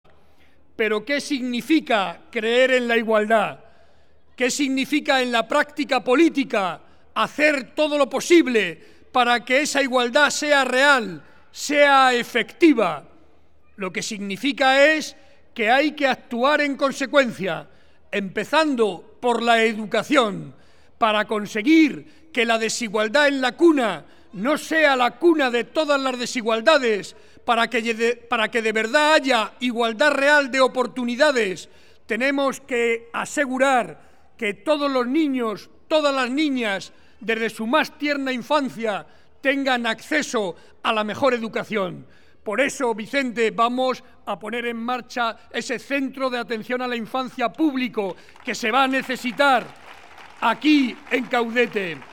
«Si hay quien no cree en la Sanidad pública, ni en la Ley de Dependencia, ni en la ayuda a los que más lo necesitan que vote a De Cospedal. Pero si lo que queremos es trabajar para que la vida trate con dignidad a todas la personas, que me vote porque ese es mi objetivo», afirmó el presidente de Castilla-La Mancha y candidato a la reelección José María Barreda hoy en Caudete en el paseo Luis Golf ante más de 800 vecinos de la localidad.
En este contexto y al grito de «¡presidente, presidente!» Barreda aseguró que «creer en la igualdad es actuar en consecuencia, empezando por la educación, la sanidad y los servicios sociales».